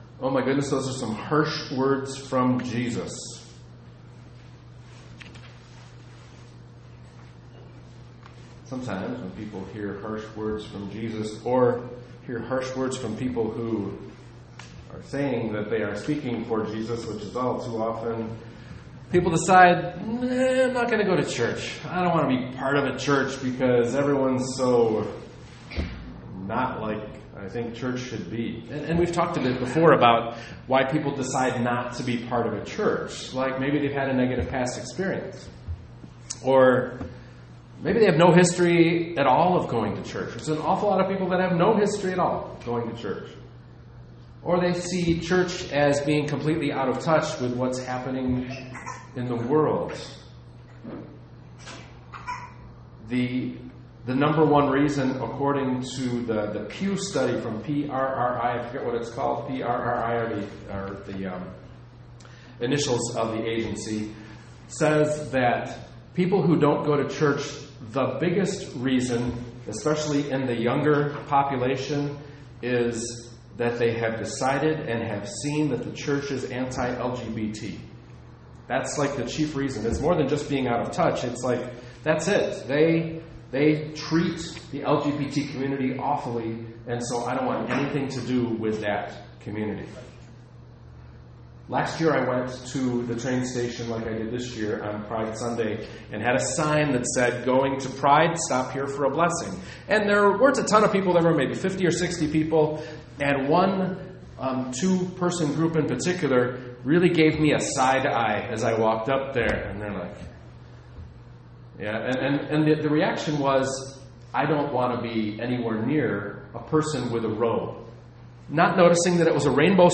Daring Sermon podcast from this past Sunday at St. Paul's UCC in Downers Grove, IL. Matthew 10:24-39.